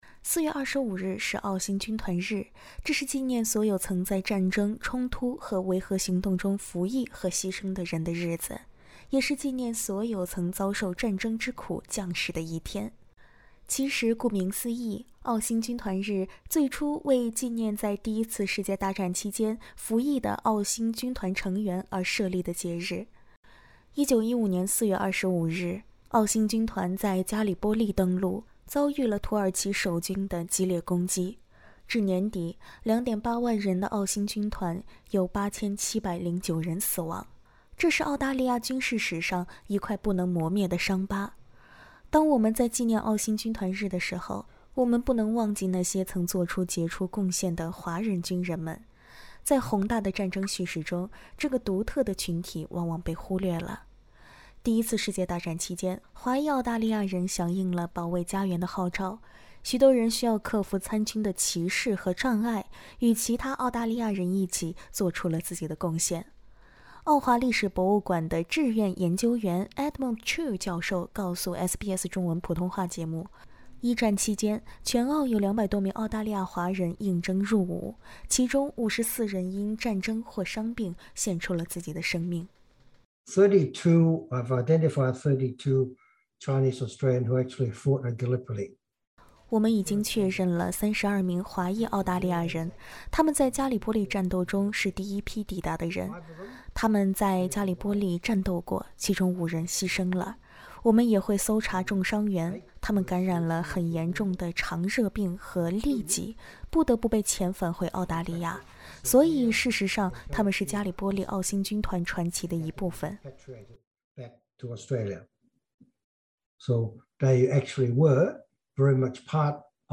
在每年的4月25日纪念澳新军团日的时候，我们也不能忘记那些曾做出杰出贡献的华裔军人们——在宏大的战争叙事中，这个独特的群体往往被忽略了。（点击上图收听采访）